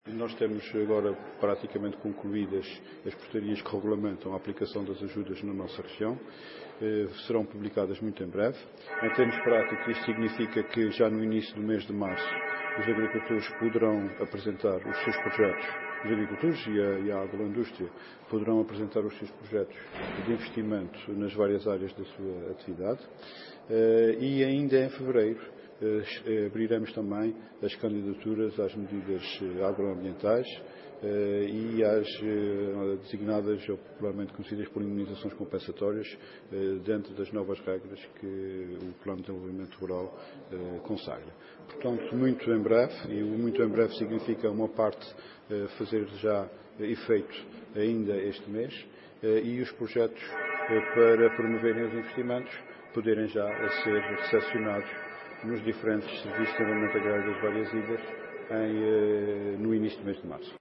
Luís Neto Viveiros, em declarações aos jornalistas, destacou que, “em termos práticos”, ainda em fevereiro, o Governo dos Açores vai abrir “as candidaturas às medidas agroambientais e às designadas ou particularmente conhecidas como indemnizações compensatórias, dentro das novas regras”.